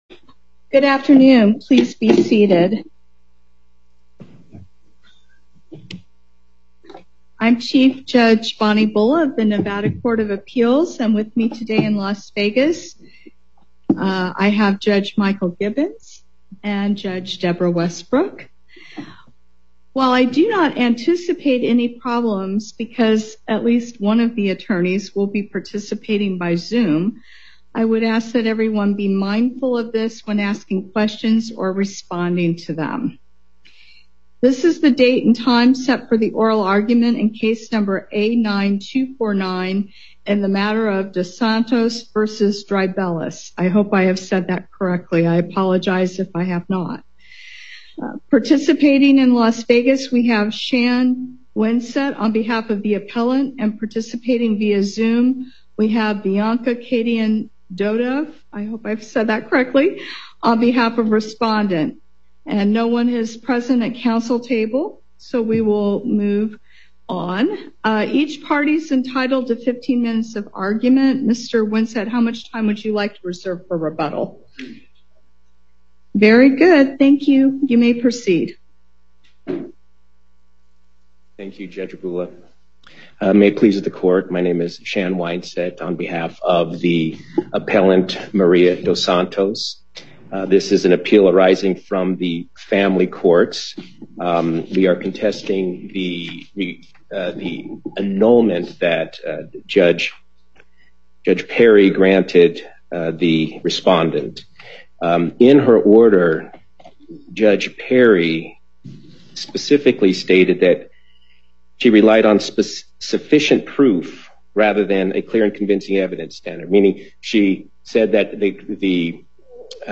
Before the Court of Appeals, Chief Judge Bulla presiding Appearances